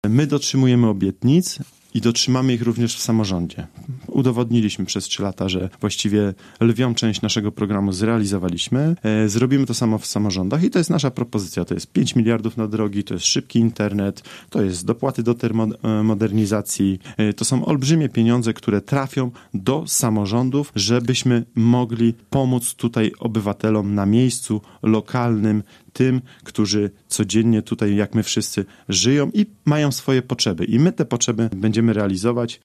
My dotrzymujemy obietnic i dotrzymamy ich też w samorządzie – tak program Prawa i Sprawiedliwości, ogłoszony wczoraj na ogólnopolskiej konwencji partii, ocenia dzisiaj nasz poranny gość Sebastian Pieńkowski, przewodniczący gorzowskiej Rady Miasta z PiS.